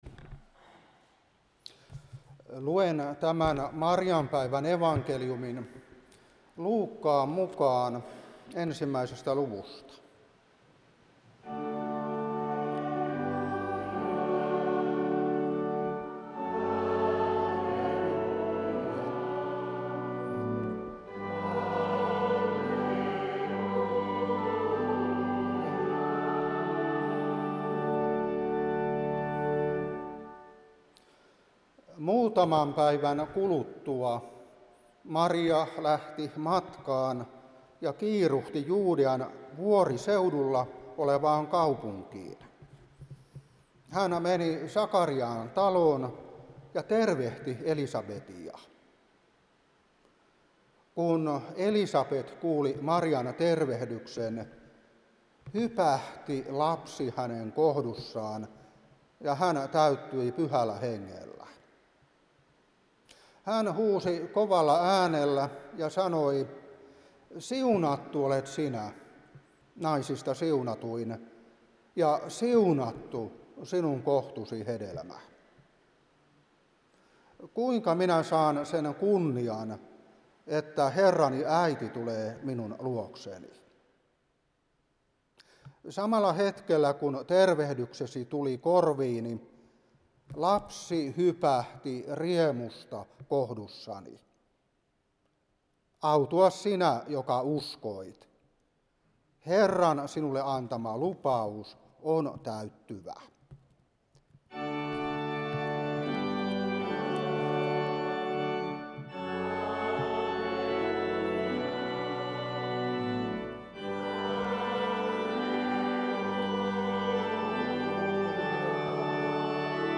Saarna 2026-3 Luuk.1:39-45.